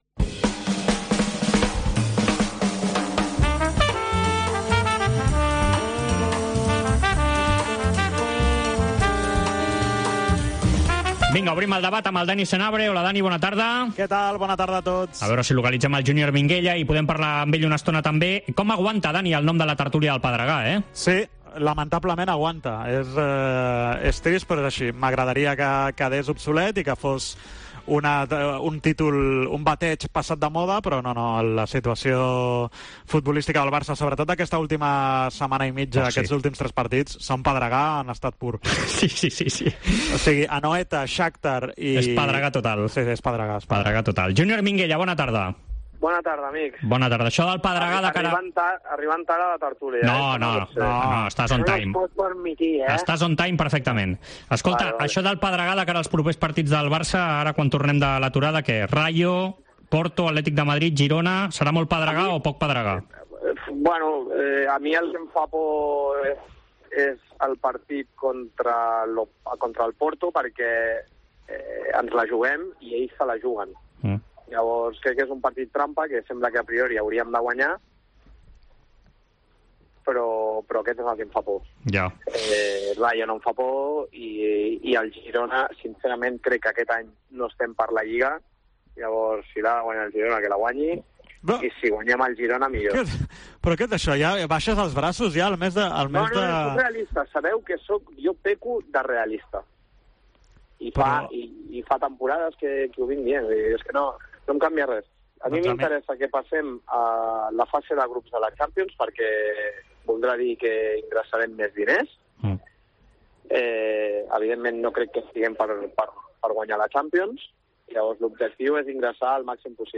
El debat Esports COPE
AUDIO: Els dos col·laboradors de la Cadena COPE repassen l'actualitat esportiva de la setmana.